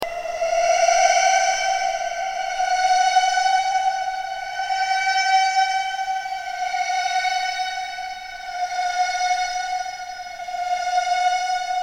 Download Siren sound effect for free.
Siren